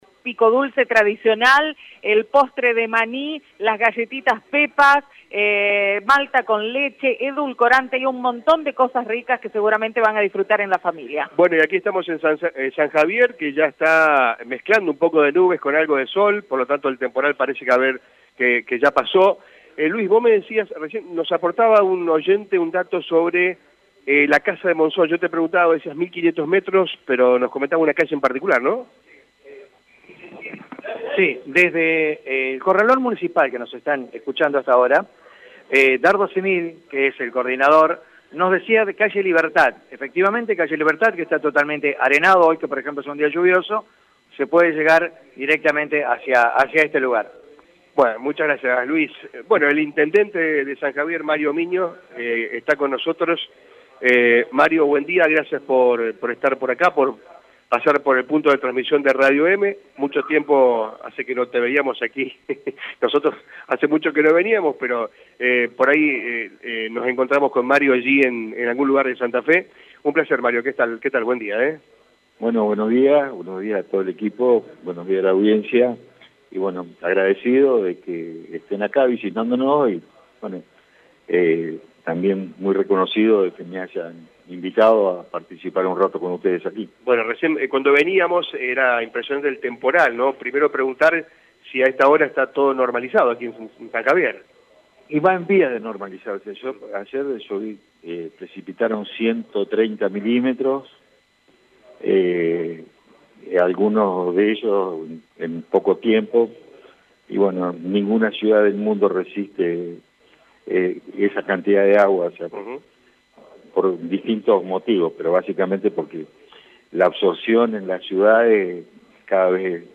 Esta mañana el intendente de San Javier, Mario Migno, dialogó con Radio EME, en el marco de la cobertura especial que la radio lleva a cabo en esa ciudad.